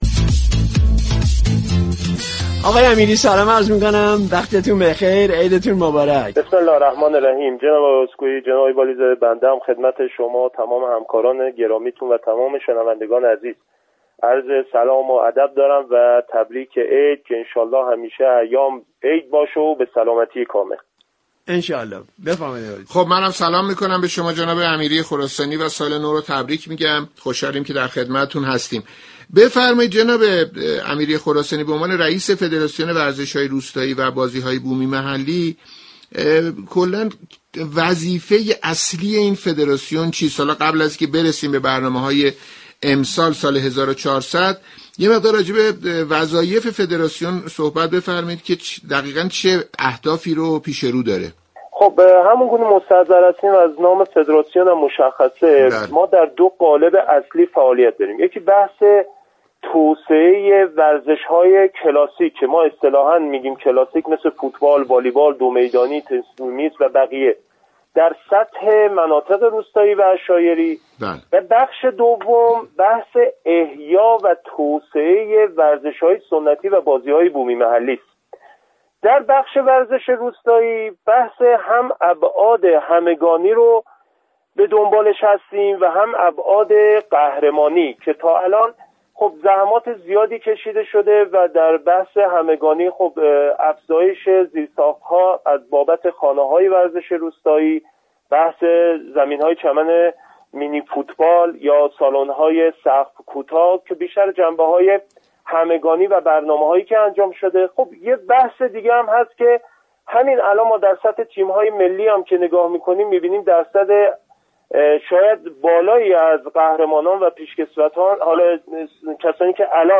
به گزارش رادیو ورزش؛ امیری خراسانی، رئیس فدراسیون ورزش های روستایی و بازیهای بومی محلی، برنامه های این فدراسیون در سال جدید را تشریح كرد. شما مخاطب محترم می توانید از طریق فایل صوتی پیوست شنونده این مصاحبه باشید.